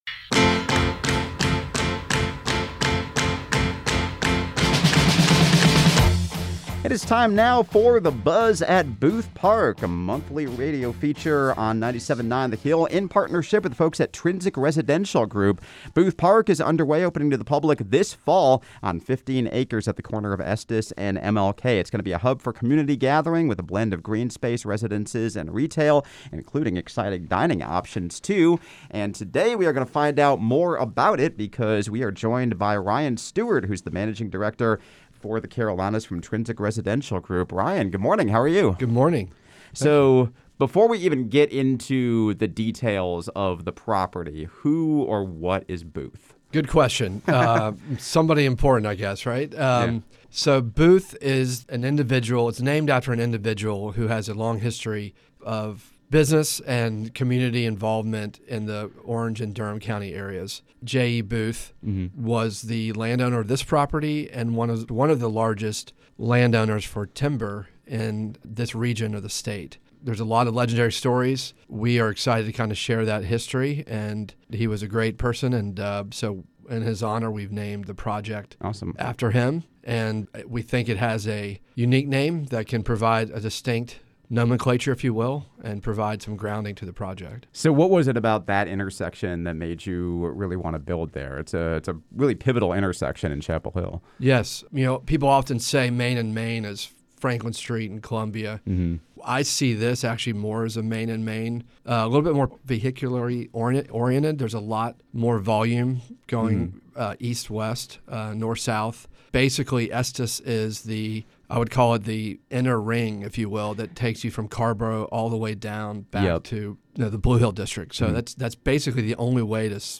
“The Buzz at Booth Park” is an exclusive radio presentation in partnership with Trinsic Residential Group on 97.9 The Hill. Booth Park is a new mixed-use neighborhood and community space on the corner of Estes and MLK — with residences, retail and public spaces across 15 acres.